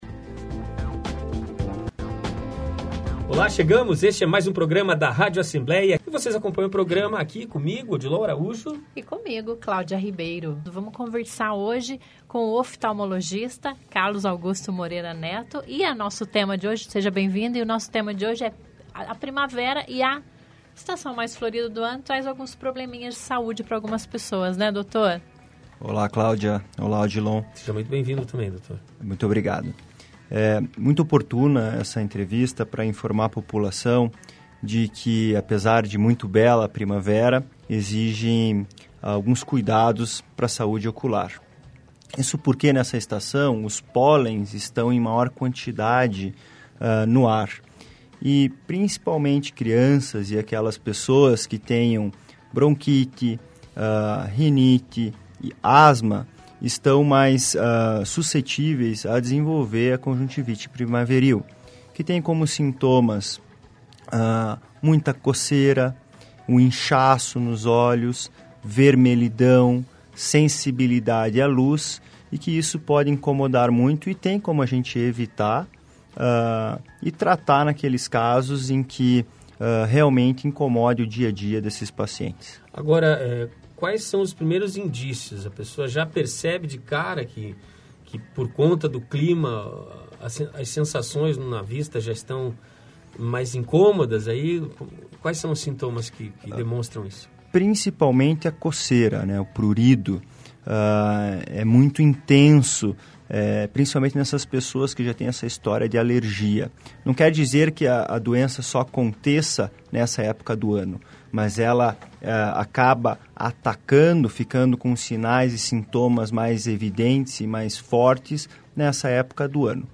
Causas, tratamentos para a conjuntivite primaveril são temas da nossa entrevista